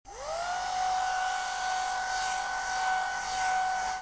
Asciugacapelli, phon
Suono di asciugacapelli tenuto in mano con movimento aria.